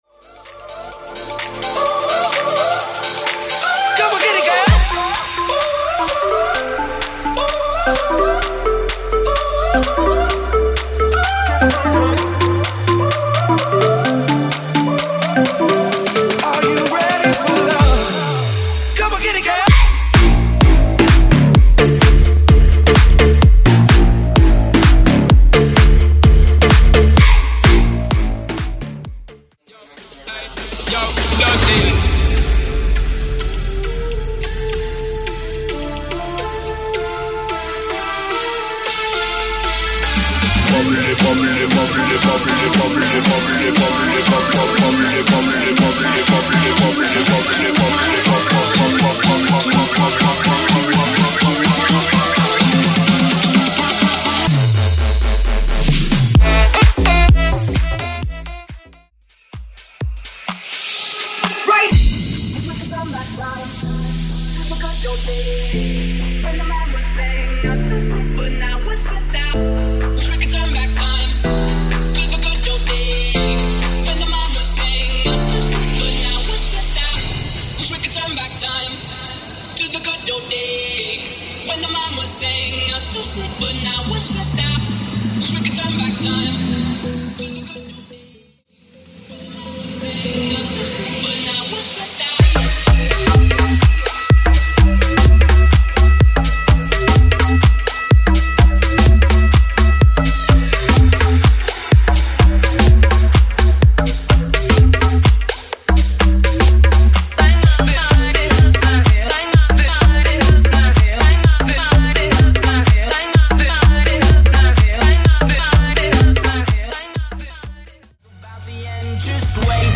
Genre: DANCE
Clean BPM: 126 Time